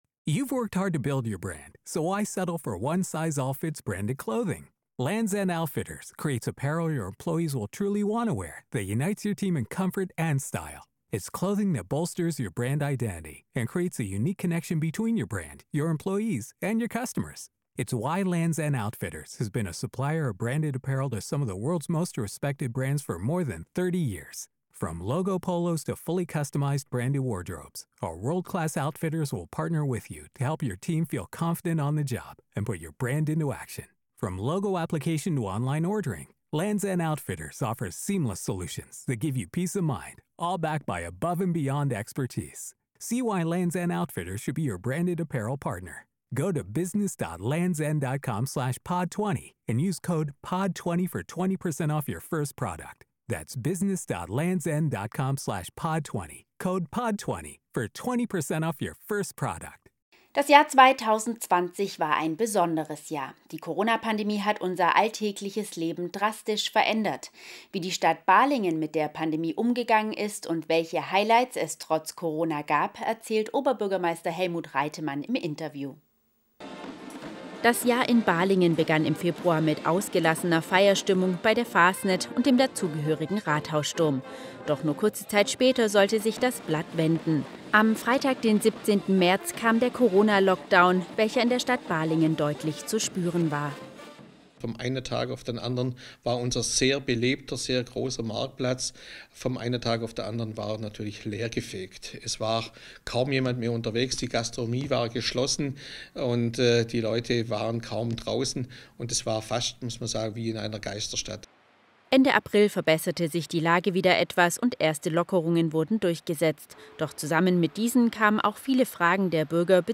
Rückblick 2020 & Ausblick: Balingen | Interview mit OB Helmut Reitemann